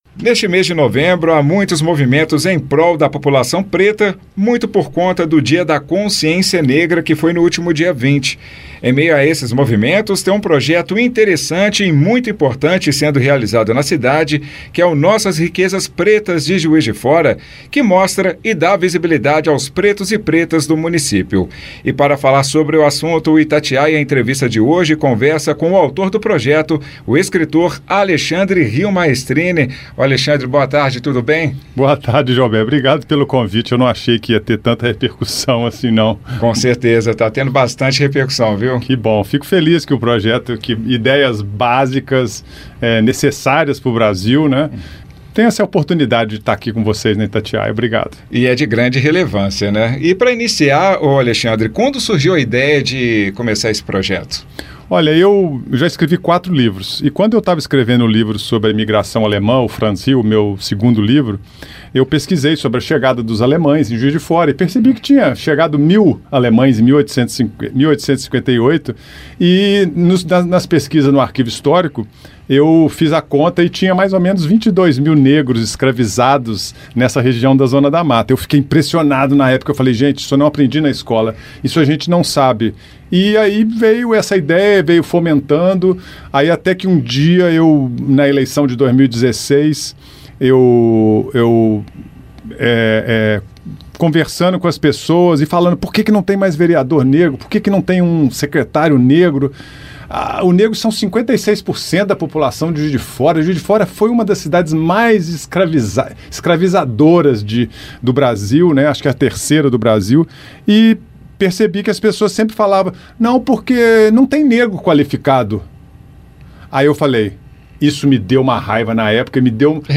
Itatiaia-Entrevista-Nossas-Riquezas-Pretas-JF.mp3